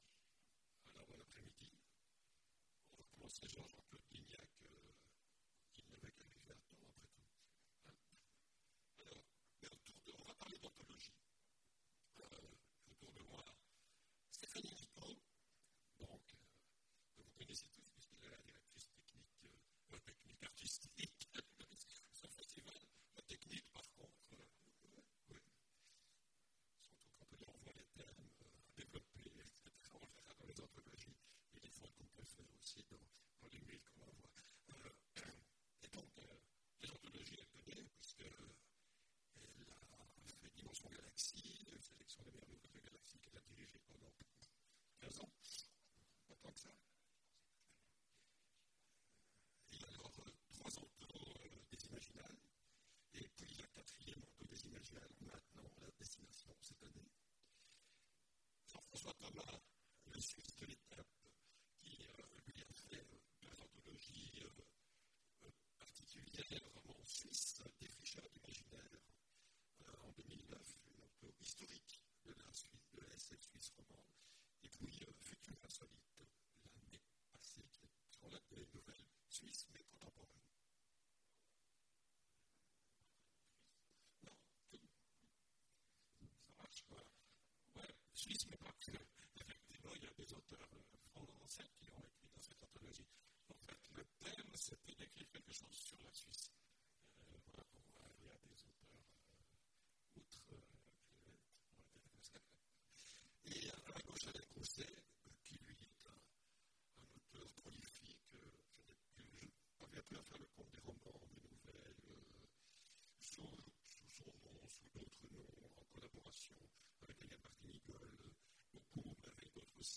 Mots-clés Edition Conférence Partager cet article